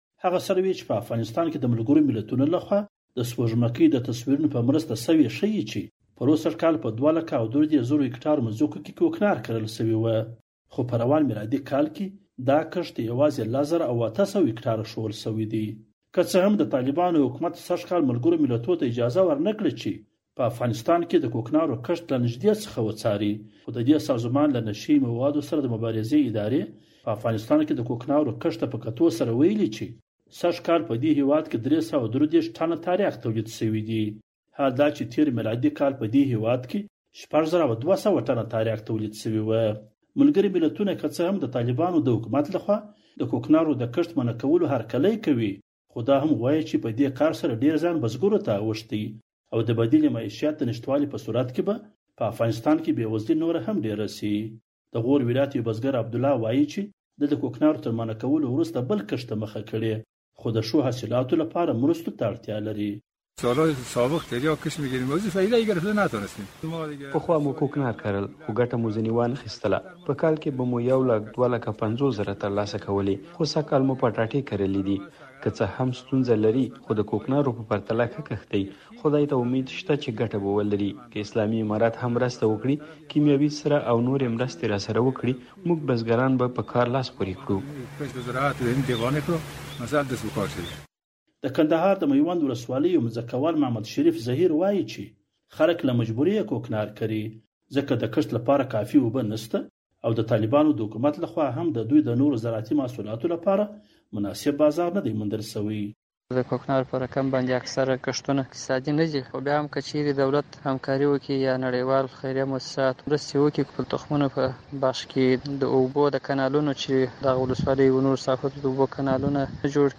د زهرو کاروان راپور